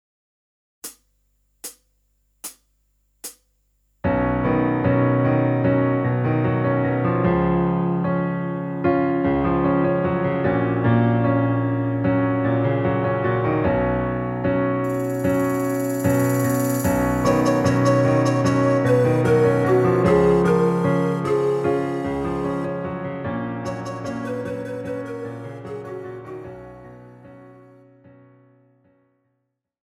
Žánr: Pop
BPM: 75
Key: H
MP3 ukázka s ML